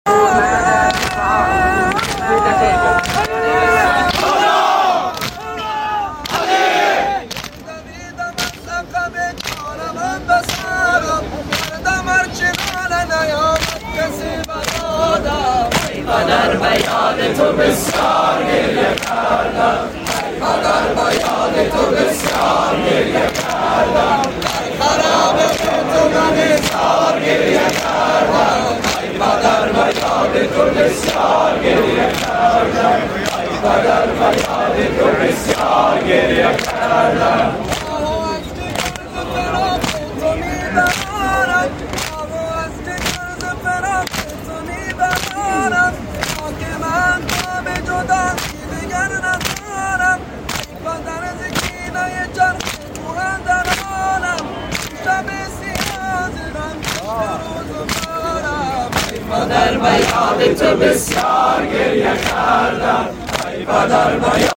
Sina Zani Afghani Bayn ul sound effects free download
Sina Zani Afghani Bayn ul Haramayn Karbala
سینه زنی افغانی در بین الحرمین کربلای معلا